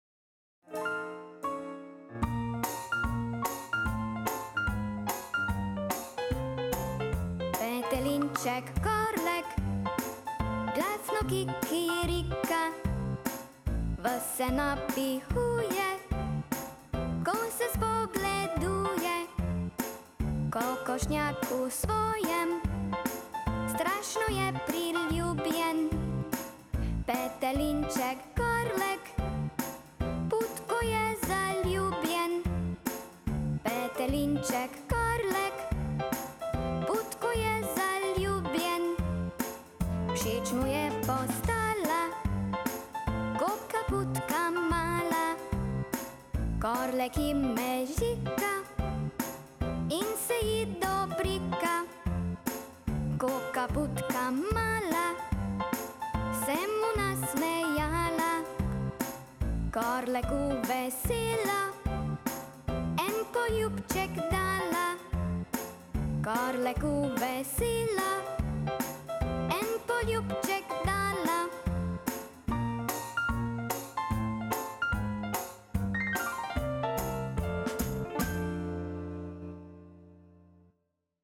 Petelinček Korlek - Otroška pesmica